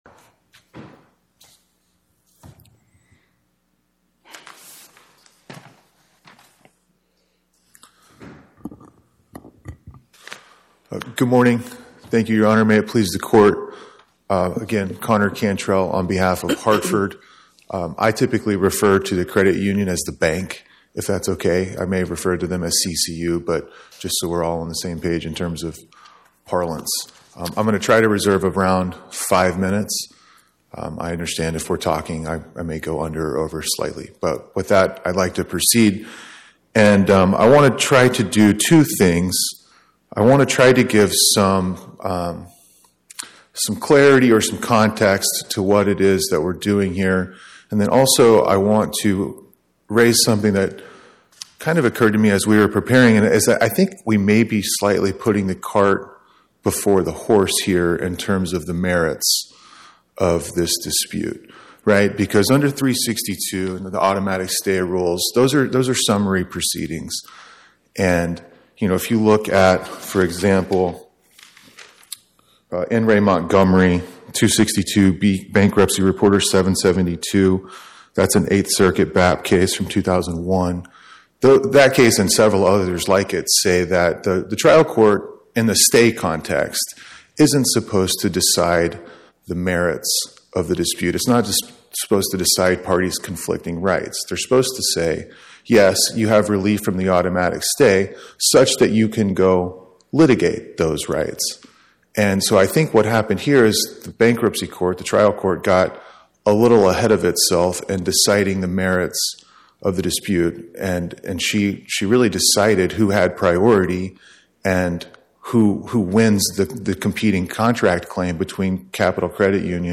Oral argument argued before the Eighth Circuit U.S. Court of Appeals on or about 08/26/2025